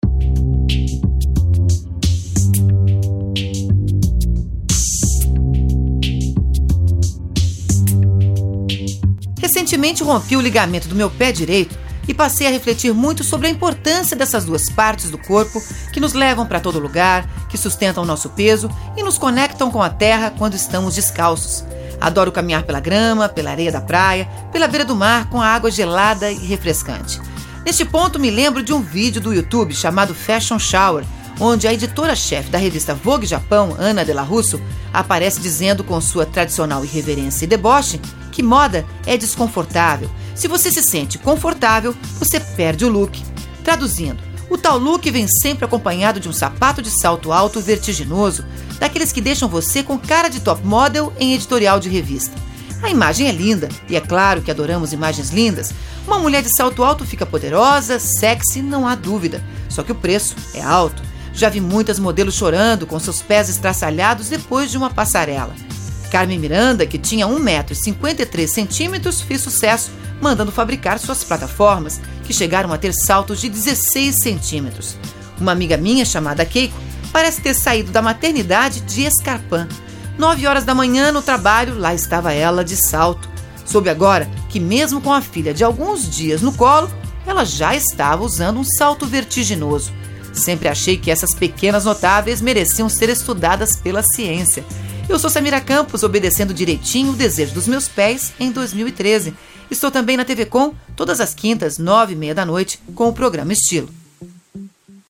na rádio Itapema FM - SC